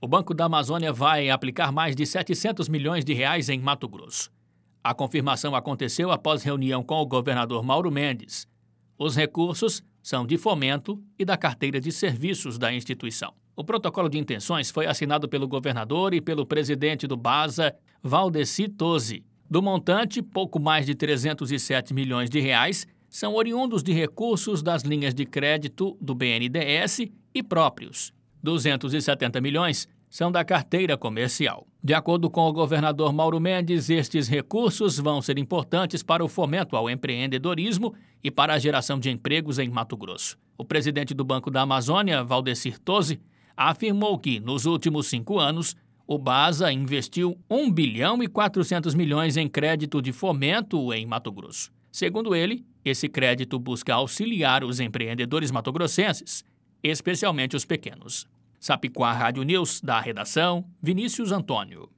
Boletins de MT 09 mar, 2022